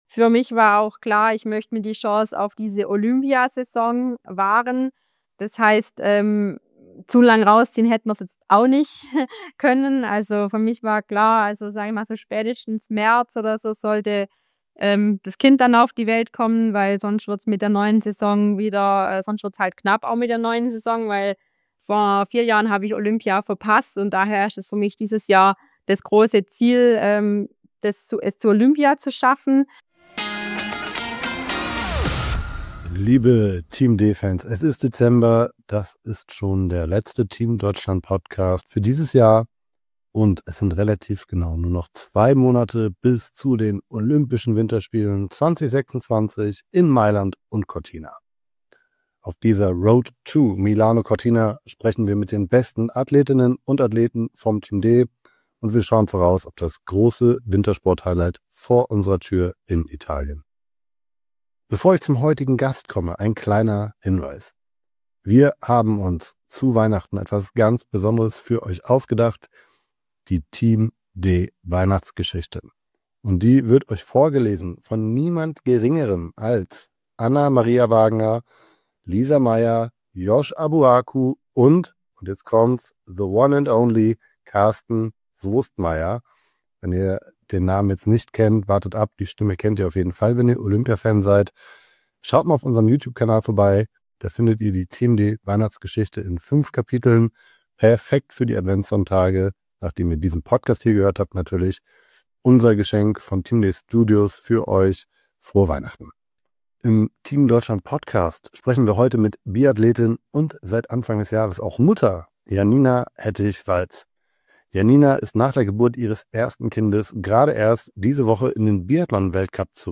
Wir sprechen heute mit Biathletin und seit Anfang des Jahres auch Mutter Janina Hettich-Walz. Janina ist nach der Geburt ihres ersten Kindes gerade erst in den Biathlon-Weltcup zurückgekehrt.